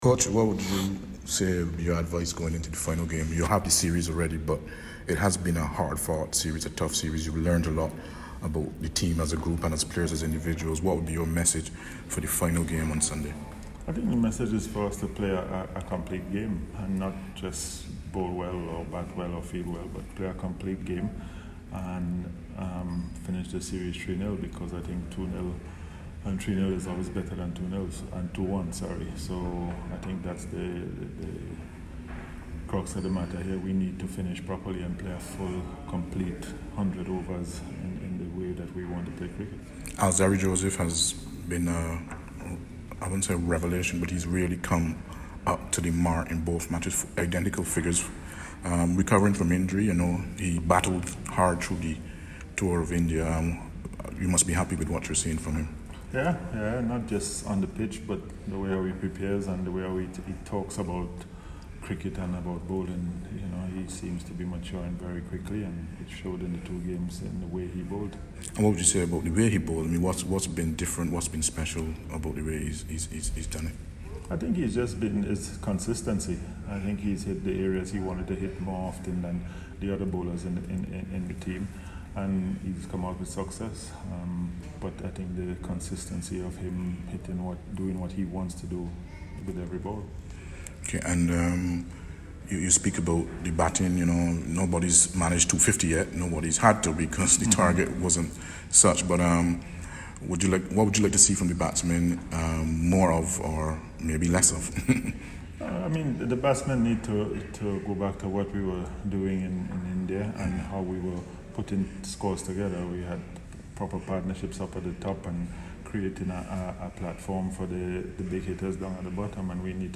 West Indies Head Coach Phil Simmons spoke to CWI Media ahead of the third and final match of the Colonial Medical Insurance One-Day International Series against Ireland.